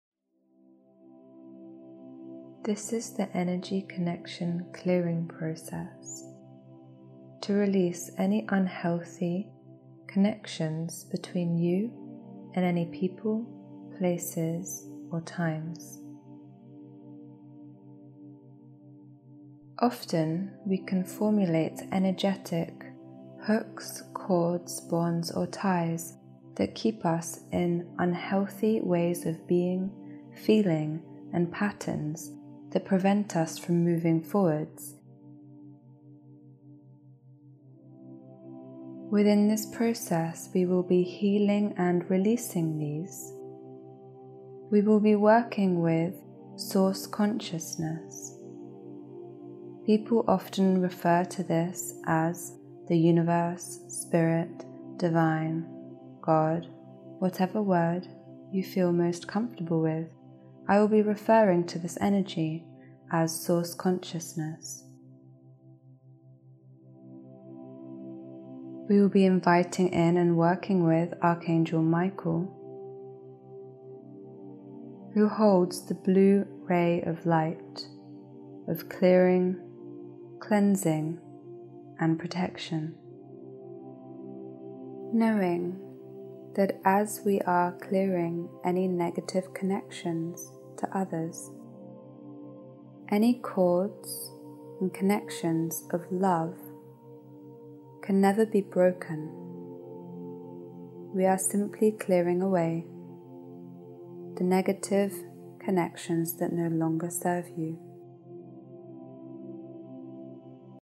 They use advanced Audio Entrainment techniques to allow Healing in the subconscious mind.